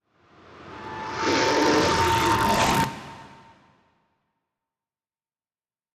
Minecraft Version Minecraft Version latest Latest Release | Latest Snapshot latest / assets / minecraft / sounds / ambient / nether / warped_forest / addition6.ogg Compare With Compare With Latest Release | Latest Snapshot